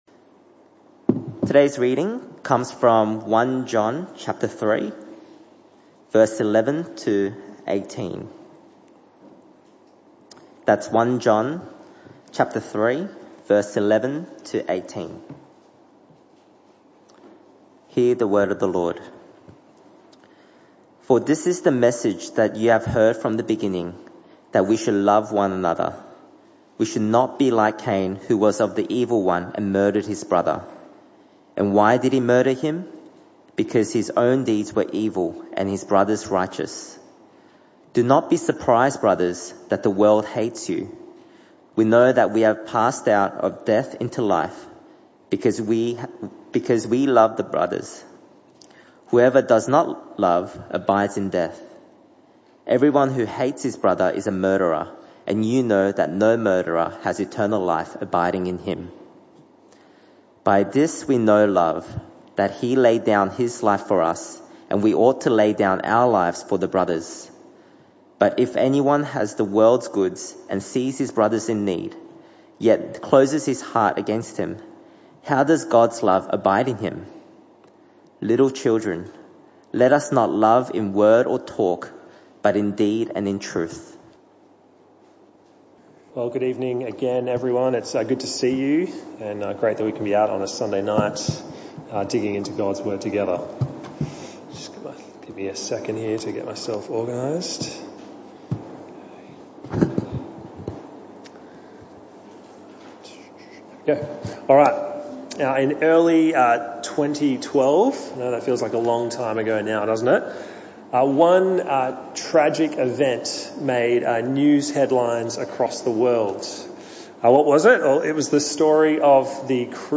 This talk was part of the AM/PM Service series entitled Loving Your Church.